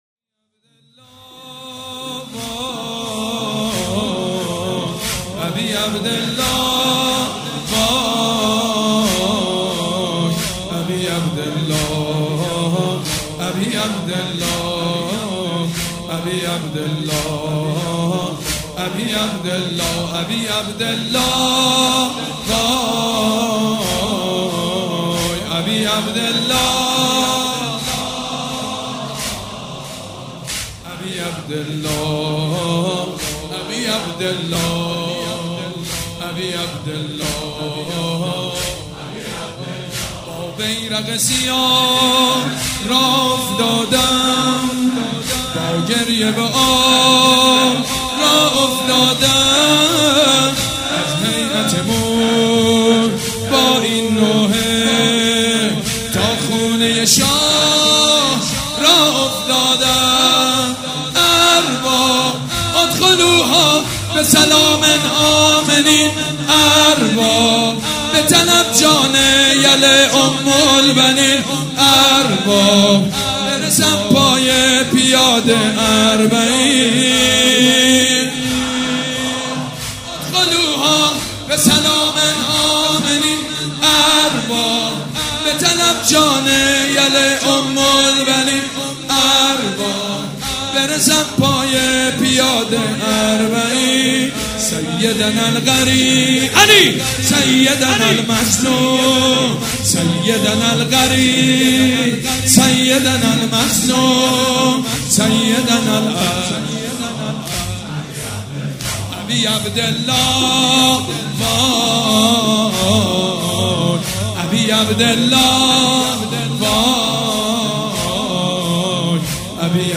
شب پنجم محرم الحرام‌ پنجشنبه ۱5 مهرماه ۱۳۹۵ هيئت ريحانة الحسين(س)
مداح حاج سید مجید بنی فاطمه
مراسم عزاداری شب پنجم